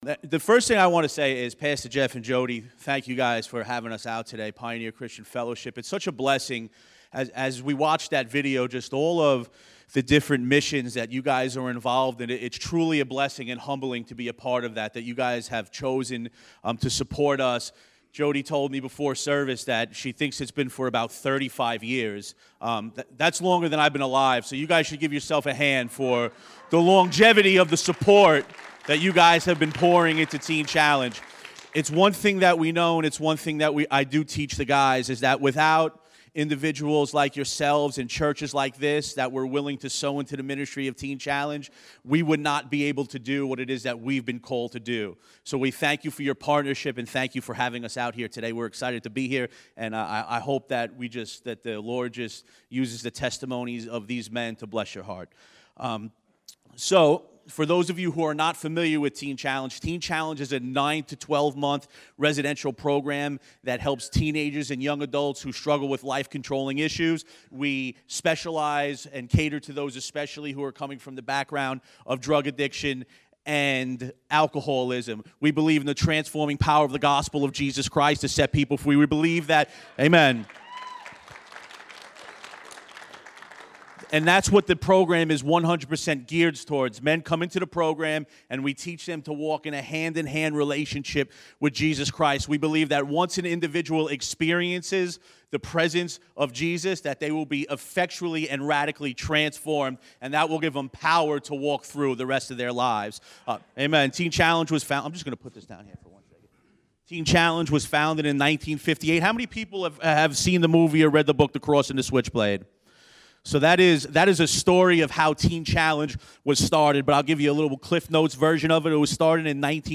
Here from Buffalo Adult & Teen Challenge as they minister to us here at PCF!